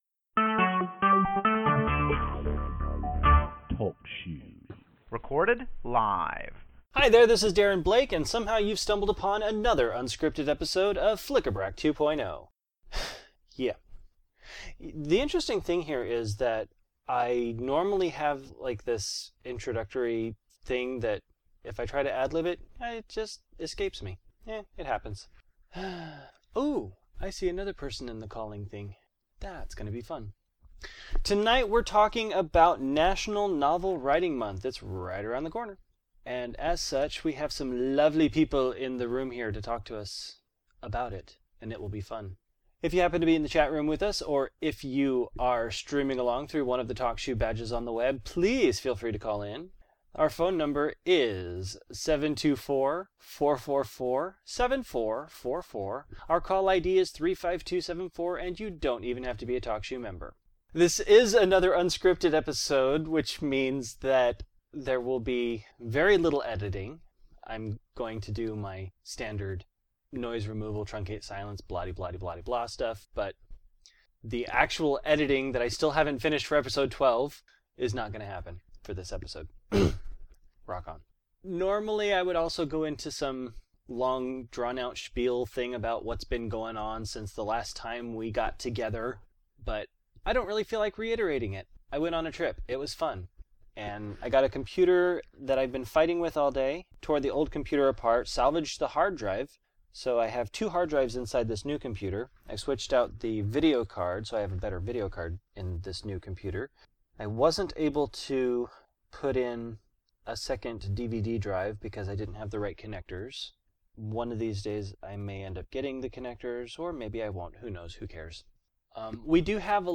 I was without my podcasting equipment and all the different sound files I needed… but I still wanted to get together with the gang to talk about everyone’s favorite November Writing Event. So I fired up my netbook (remember those?) and did an Unscripted episode.